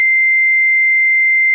ZUMBADOR SOMIDO CONTINUO Y/O INTERMITENTE - Ø 50MM
80dB
K50-CONTINUO
K50-CONTINUO.wav